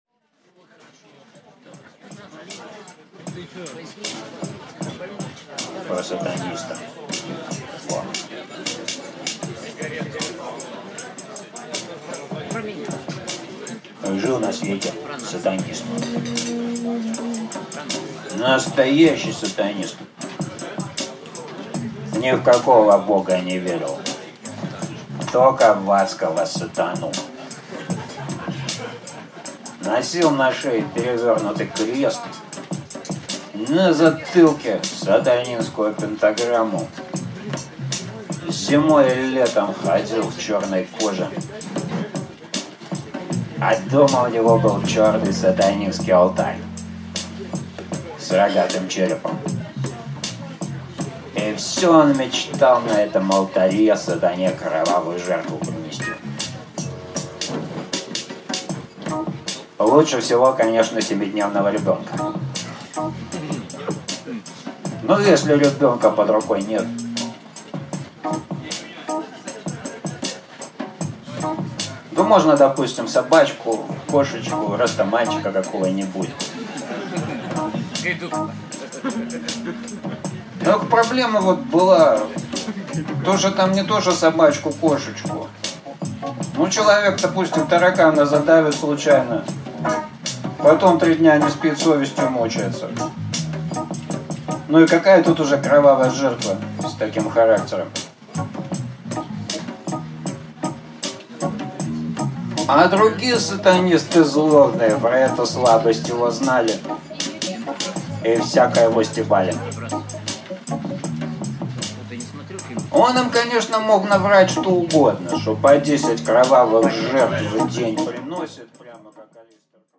Аудиокнига Про сатаниста и богиста | Библиотека аудиокниг